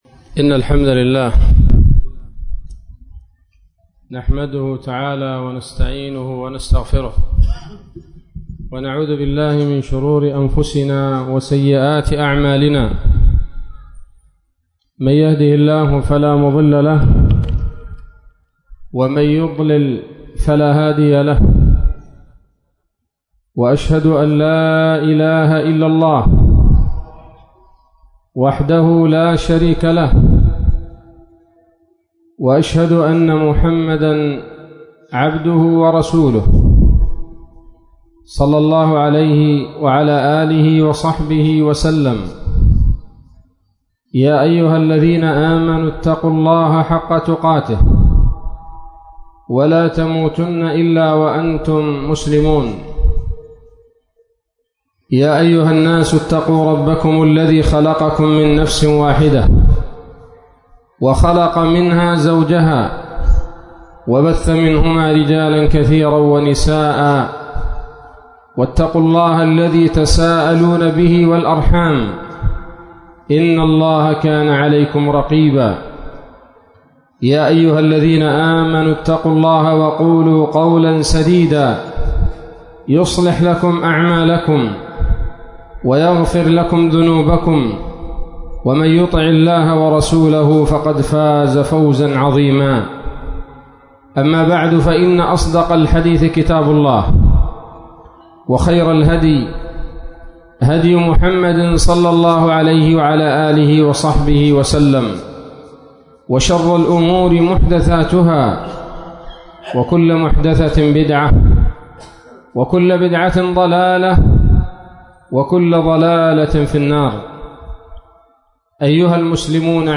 غربة-الإسلام-والعودة-إلى-الدين-في-مسجد-التقوى-بالمعلا.mp3